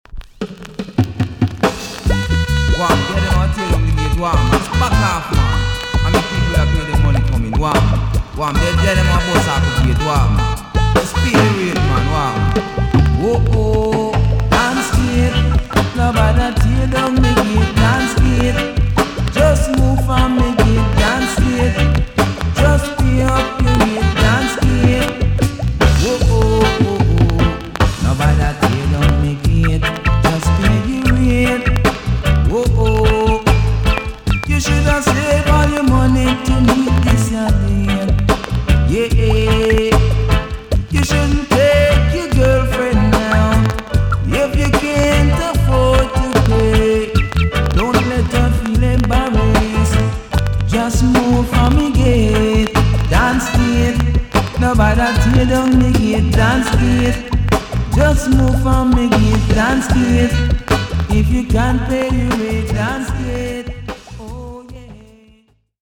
TOP >80'S 90'S DANCEHALL
VG+ 少し軽いチリノイズが入ります。
1983 , NICE EARLY DANCEHALL TUNE!!